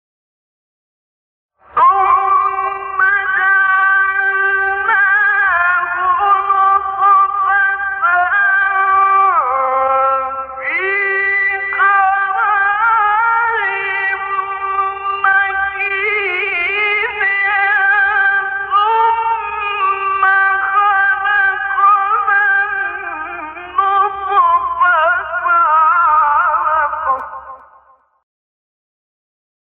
سایت-قرآن-کلام-نورانی-نهاوند-شعیشع-2.mp3